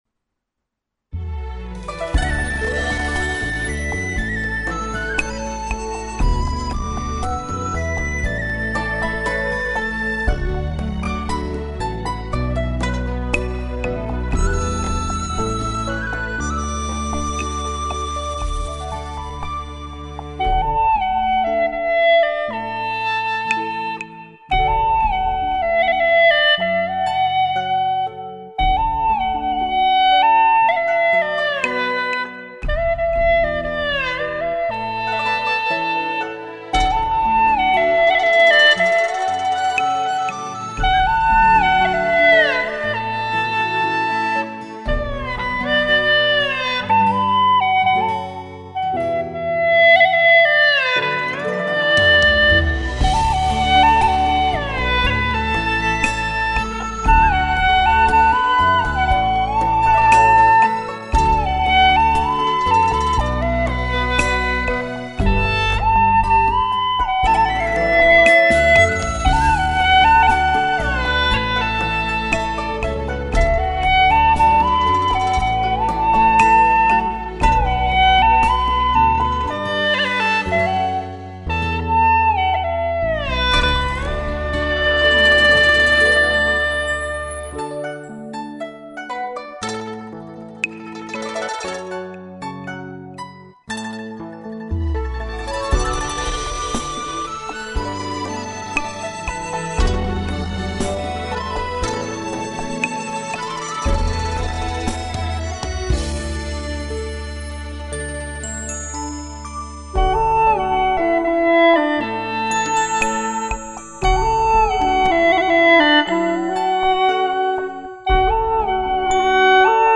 调式 : D